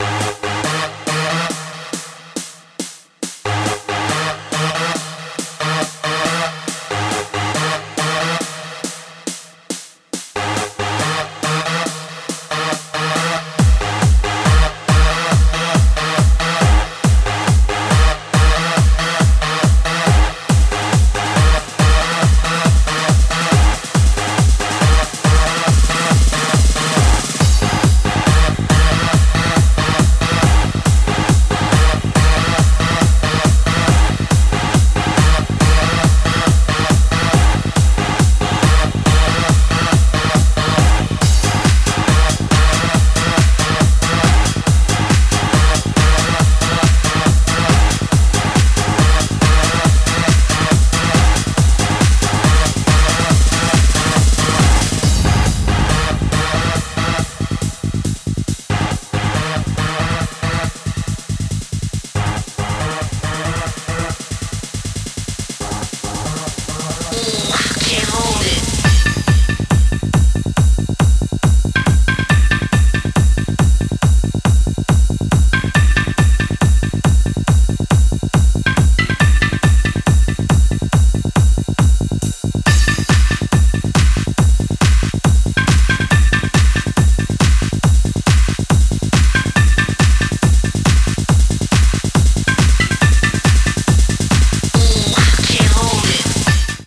Electronic
TranceHard HouseHard TranceTechnoProgressive TranceJumpstyle